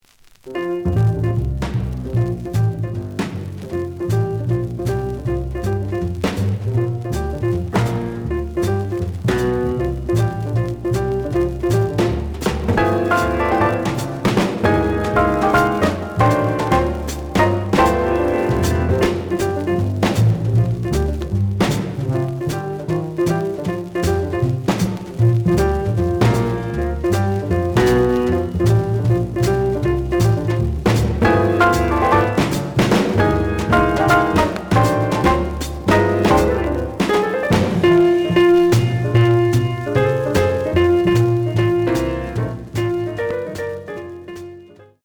The audio sample is recorded from the actual item.
●Genre: Modern Jazz
Looks good, but slight noise on both sides.)